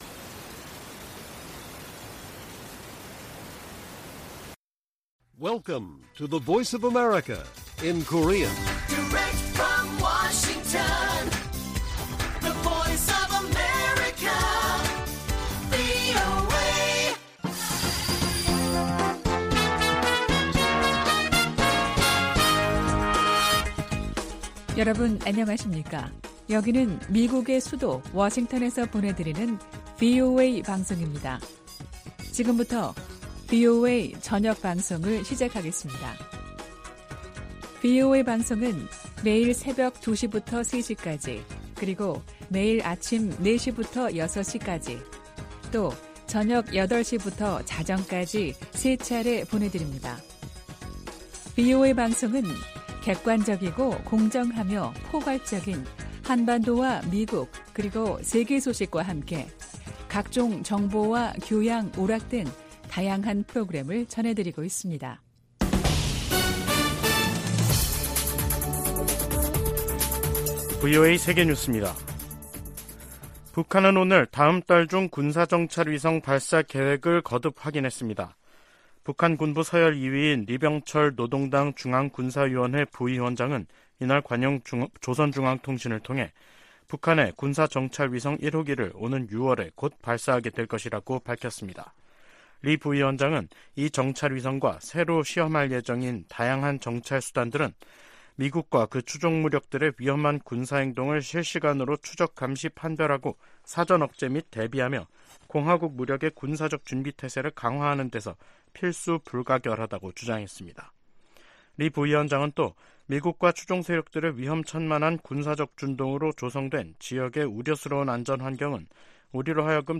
VOA 한국어 간판 뉴스 프로그램 '뉴스 투데이', 2023년 5월 30일 1부 방송입니다. 북한의 동창리 서해 위성발사장에서 로켓을 최종 장착시키는 역할을 하는 조립 건물 2개 동이 발사대 쪽으로 이동한 모습이 관찰됐습니다. 리병철 북한 노동당 중앙군사위원회 부위원장은 군사정찰위성 1호기 발사가 자위권 차원이라고 주장했습니다.